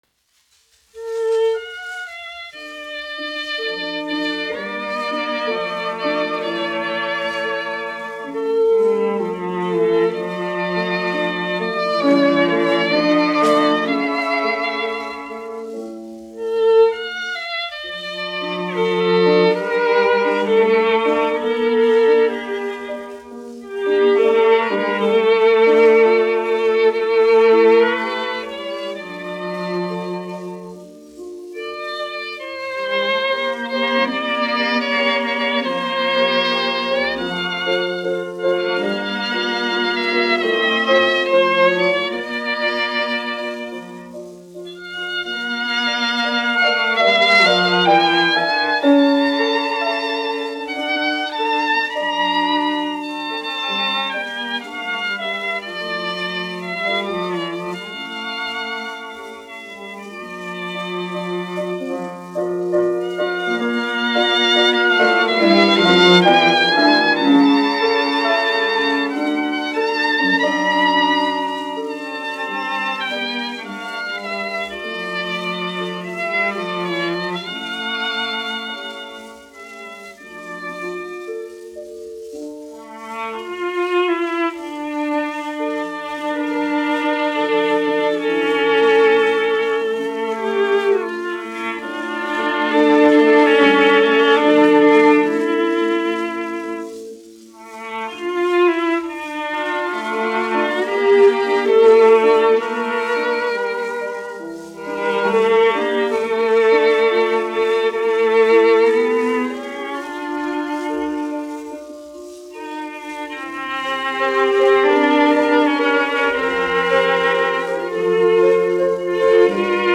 1 skpl. : analogs, 78 apgr/min, mono ; 25 cm
Klavieru trio, aranžējumi
Latvijas vēsturiskie šellaka skaņuplašu ieraksti (Kolekcija)